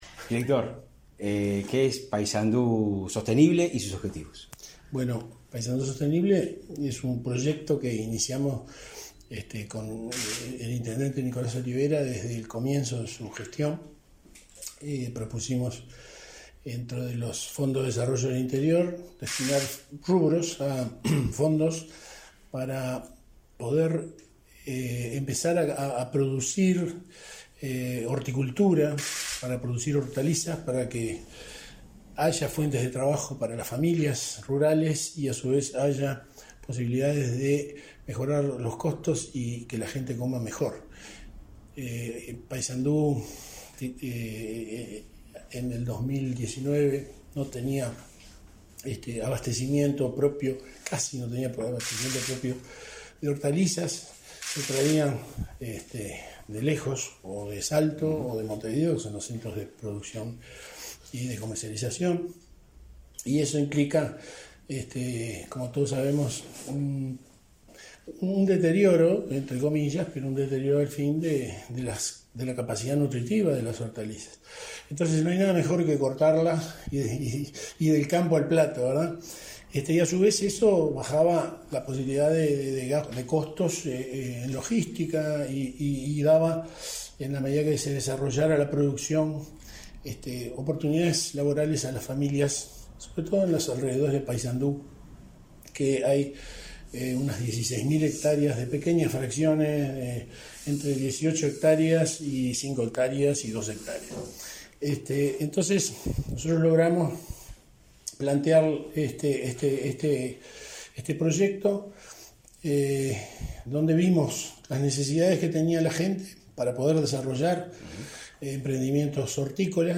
Entrevista al director de Desarrollo Estratégico y Sostenible de la Intendencia de Paysandú, Eduardo van Hoff